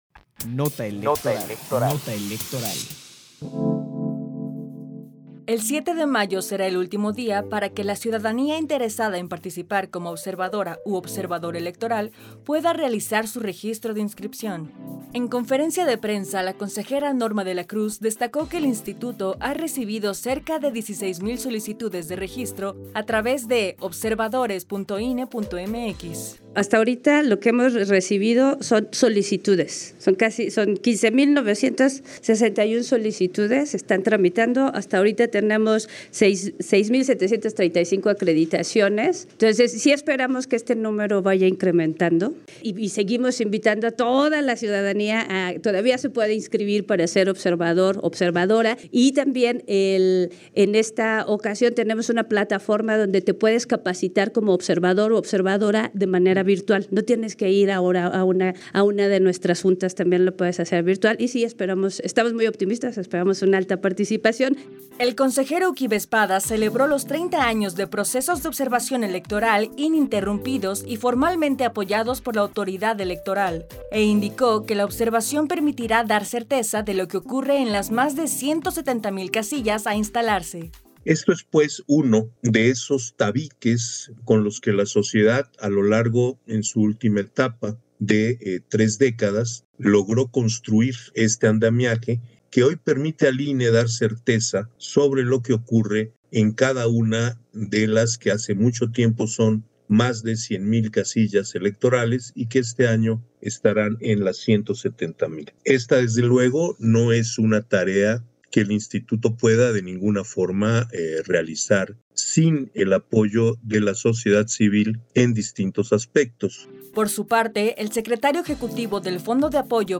PD_1593_-CONFERENCIA-DE-PRENSA-OBSERVACION-ELECTORAL-23-abril-2024 - Central Electoral